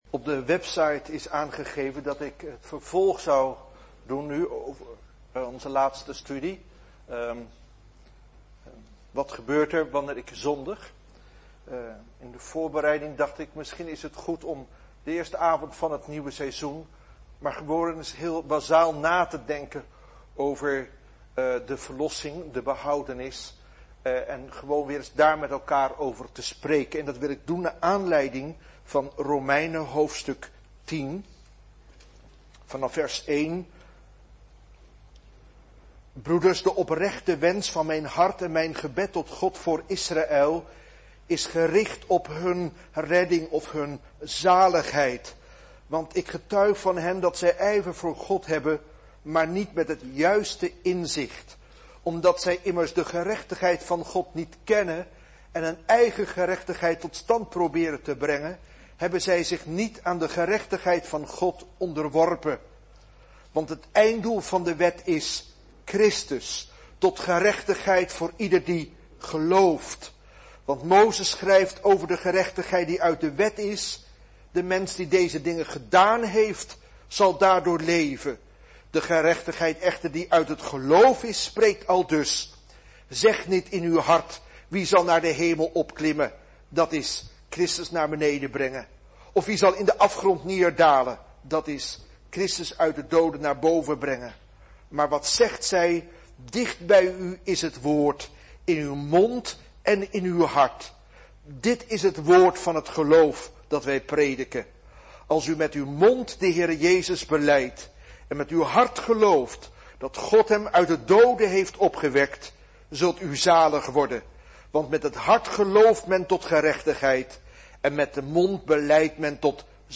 Een preek over 'Bent u gered?'.